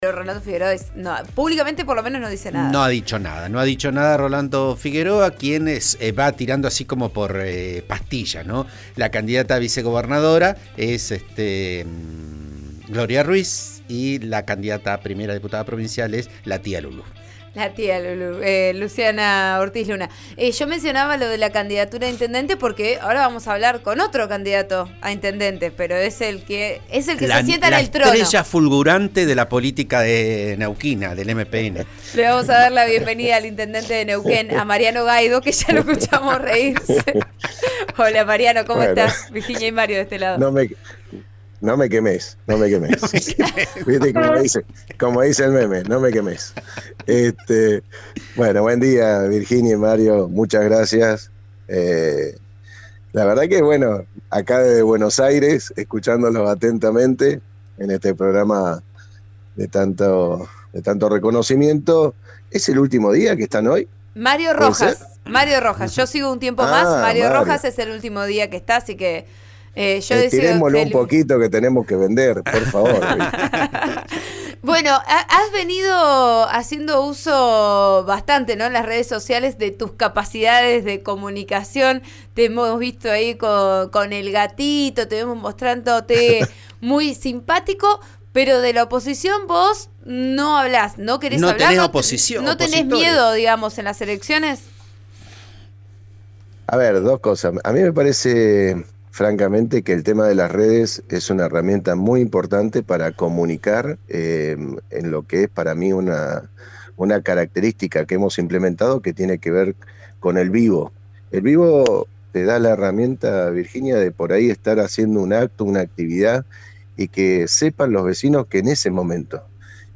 Lo confirmó el intendente de la capital neuquina en declaraciones a Vos A Diario por RN Radio.